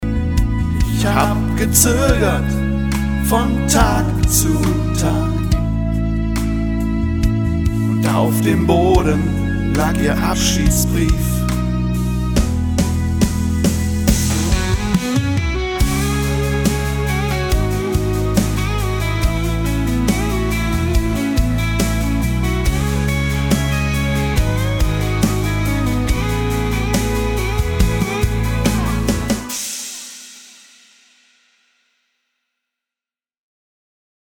[Deutschrock] Das Mädchen 🎤